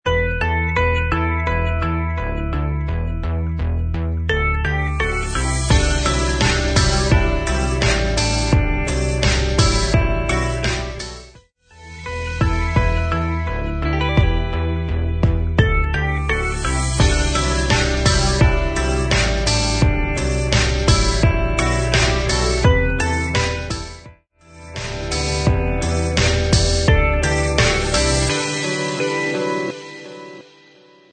Music Beds
Smooth Electronic